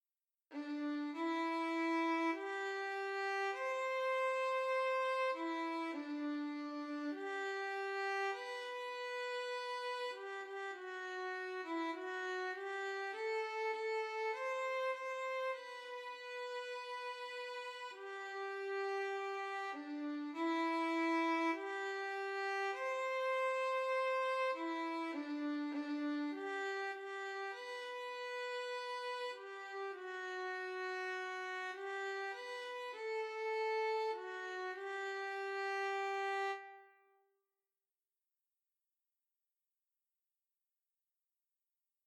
This arrangement is for easy violin in G major.
Aloha-Oe-Easy-Violin.mp3